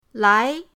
lai2.mp3